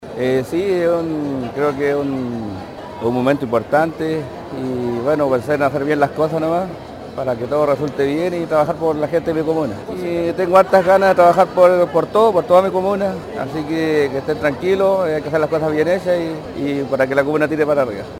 El concejal Claudio Molina tendrá su primera experiencia en el Concejo Municipal, añadiendo que entregará su mejor trabajo para la comunidad.